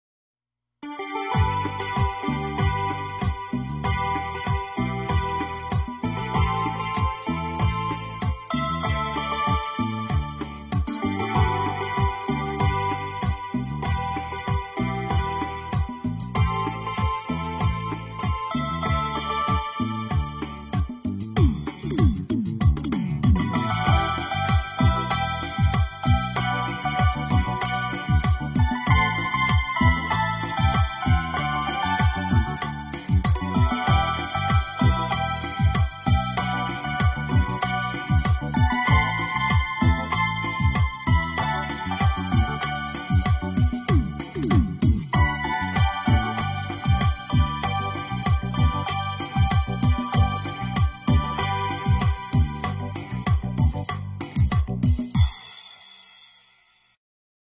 119-TexMex-3.mp3